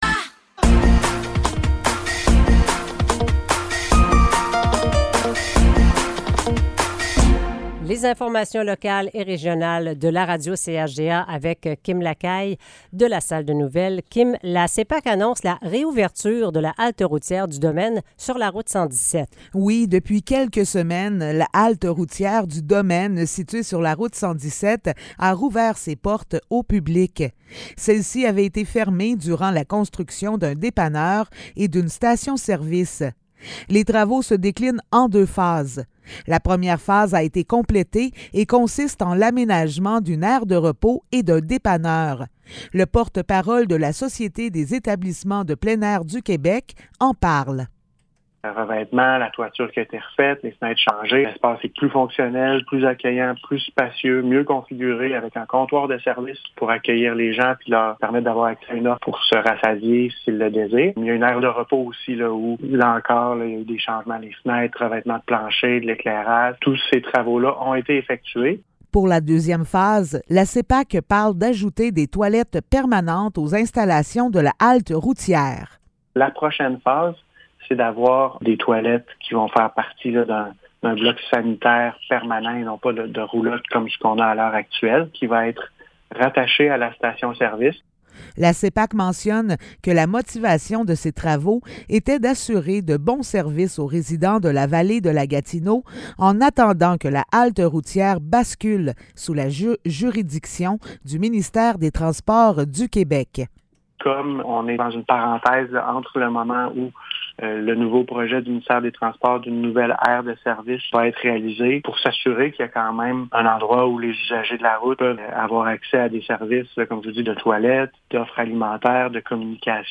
Nouvelles locales - 6 septembre 2023 - 7 h